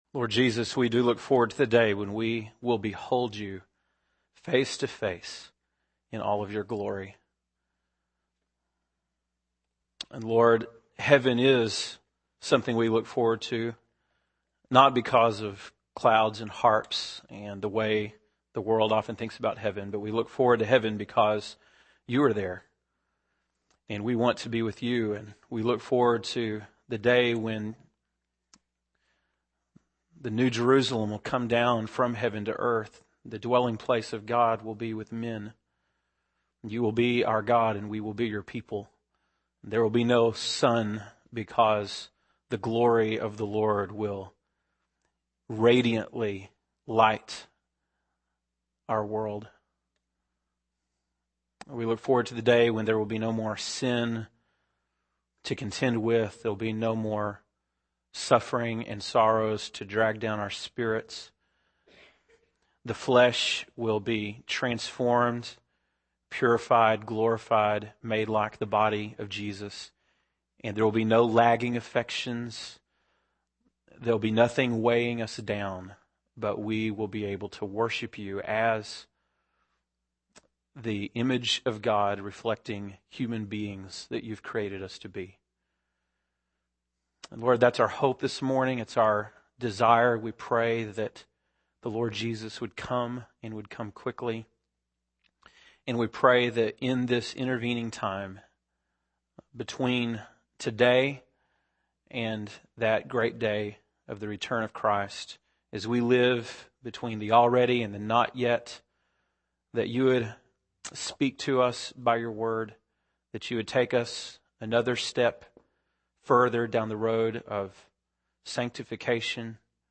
April 15, 2007 (Sunday Morning)